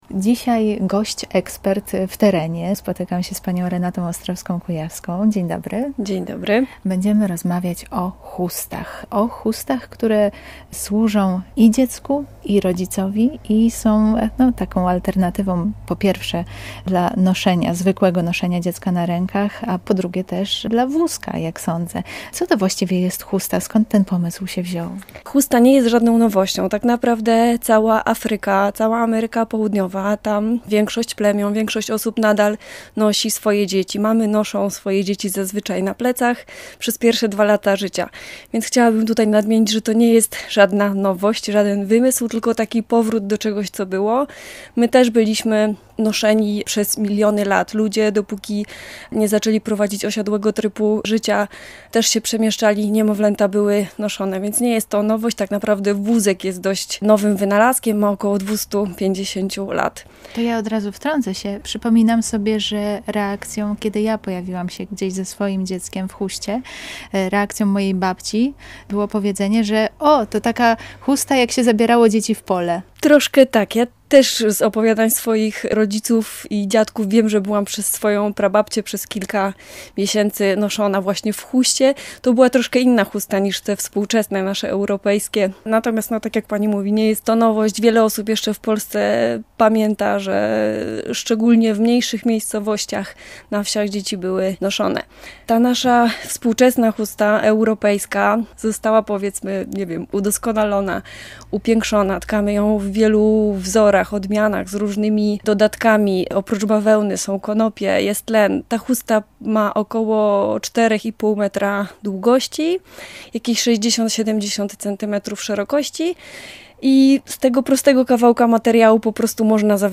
W czym pomaga rodzicom chusta i czym różni się od nosidełka? Zapraszam do wysłuchania rozmowy z doradcą chustonoszenia